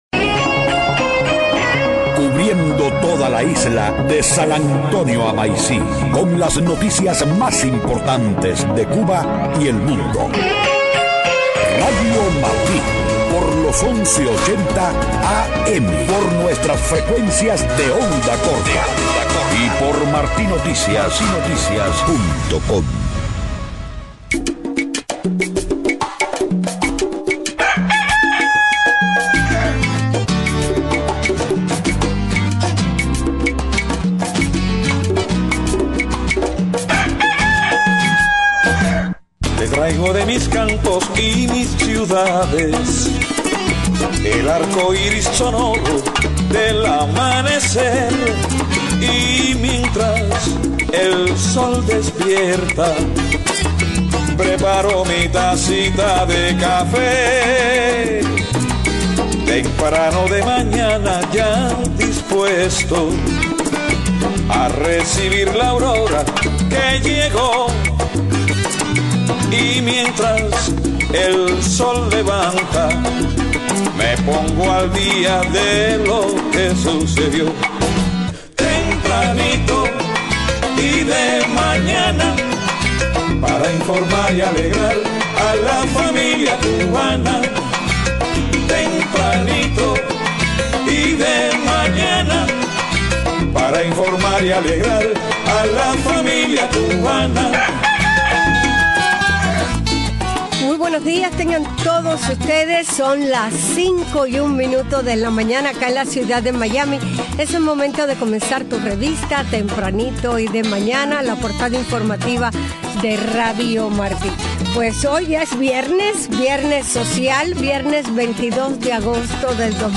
Estado del tiempo. Deportes.